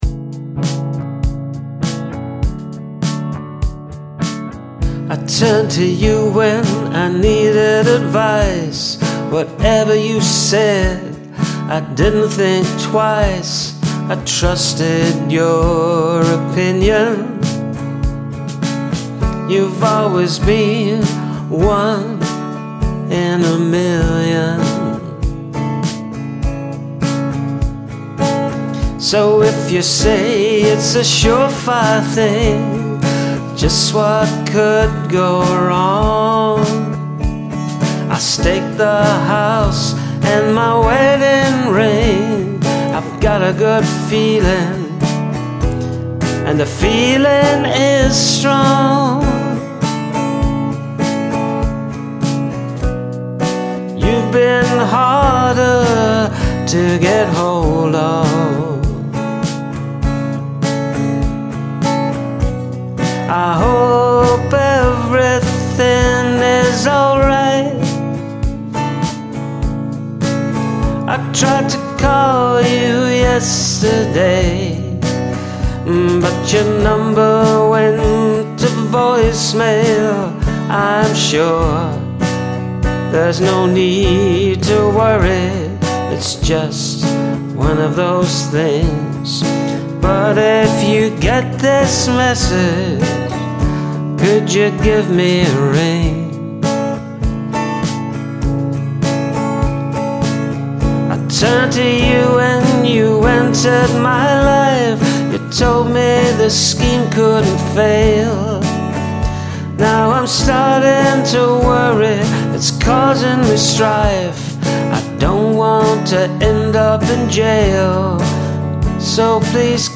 Plaintive, and rings true.
Loving the vocals and the rich guitar overtones.
Oh what a sad song…
Wonderful sound and flow to the music.